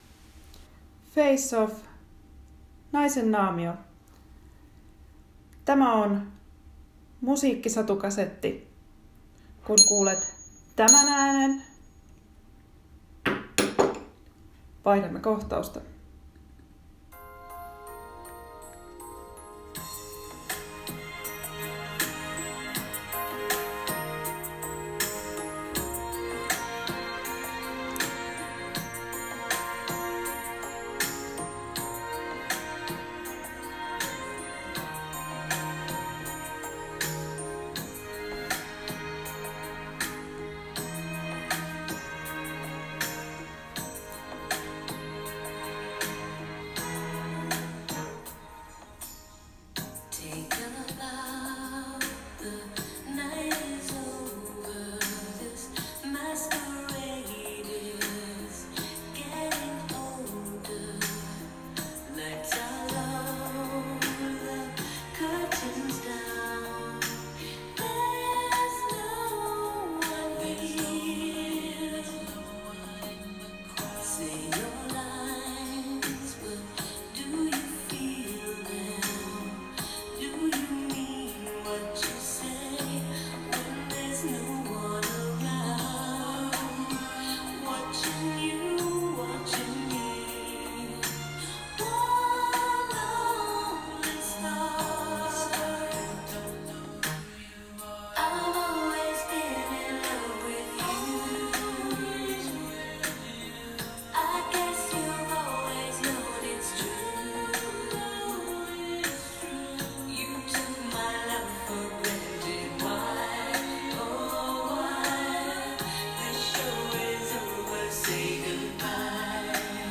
Musiikkisatukasetti: FACE OFF — NAISEN NAAMIO
Tämä kännykällä äänitetty teksti on ensimmäinen kokeiluni satukasettiformaatin parissa.